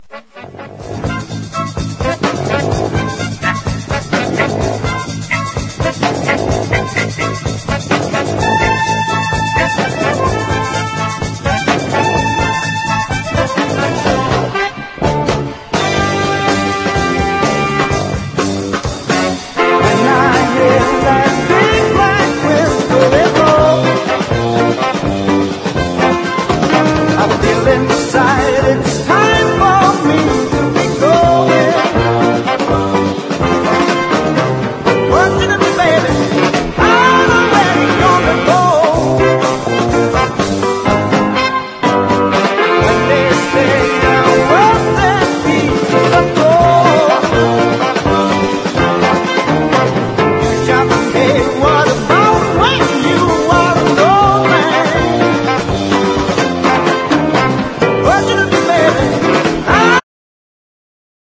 ROCK / 70'S / JAZZ ROCK